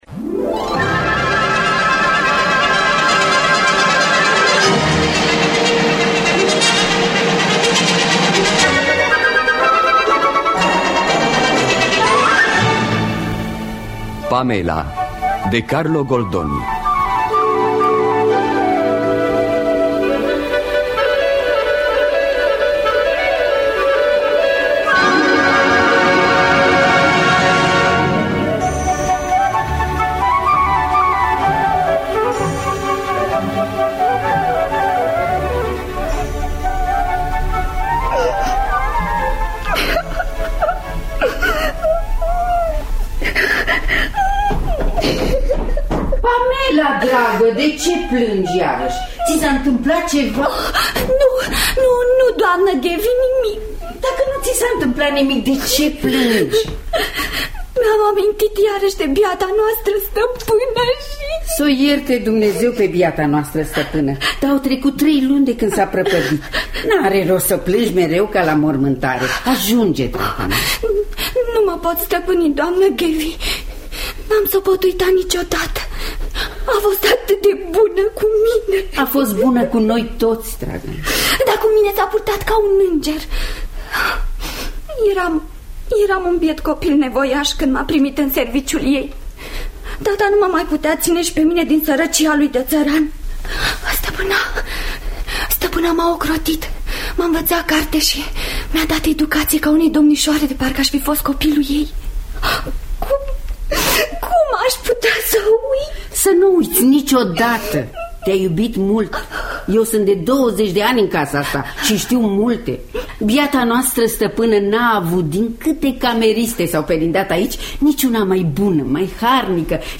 Pamela de Carlo Goldoni – Teatru Radiofonic Online